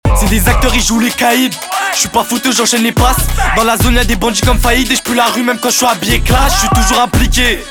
Obtenez un son vocal propre, clair et puissant directement dans Ableton Live grâce à ce rack d’effets prêt-à-l’emploi.